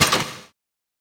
armExtension.ogg